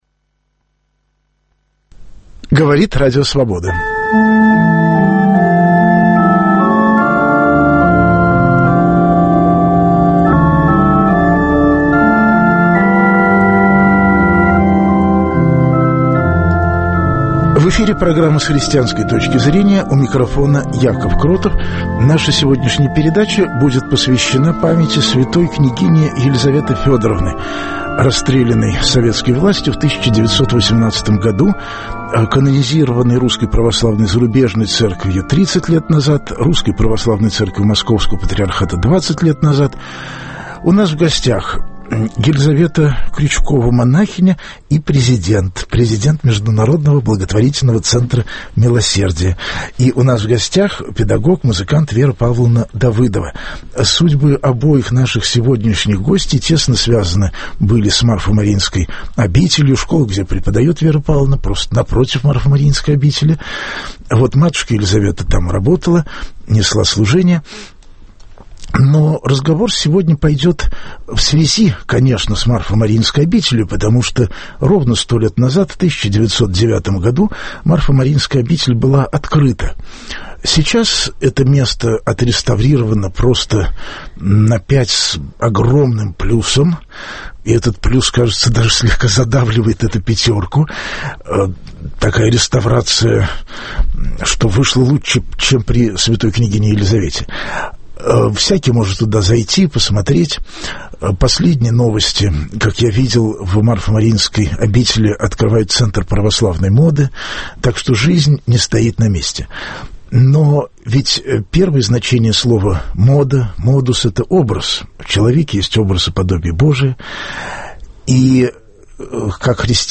Программа будет посвящена памяти святой княгини Елисаветы Федоровны - исполнилось сто лет главному делу её жизни, Марфо-Мариинской обители. В прямом эфире пойдет разговор